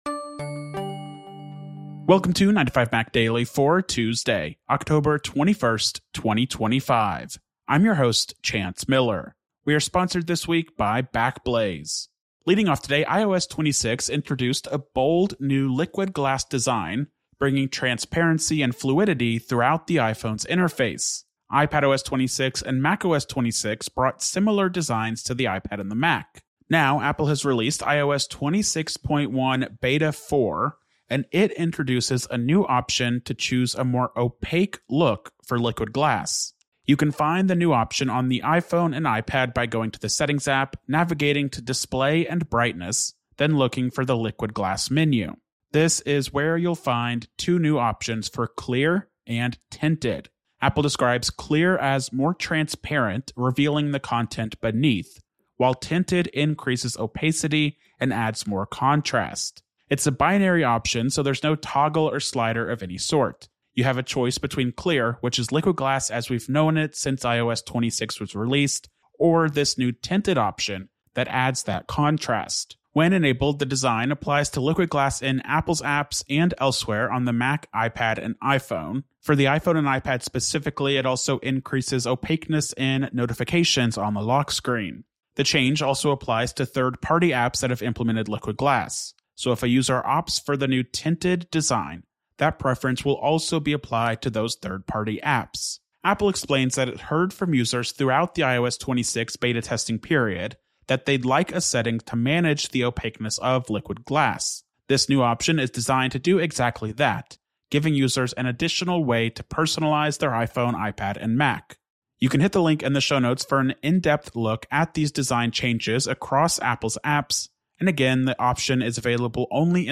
استمع إلى ملخص لأهم أخبار اليوم من 9to5Mac. 9to5Mac يوميا متاح على تطبيق iTunes وApple Podcasts, غرزة, TuneIn, جوجل بلاي، أو من خلال موقعنا تغذية RSS مخصصة لـ Overcast ومشغلات البودكاست الأخرى.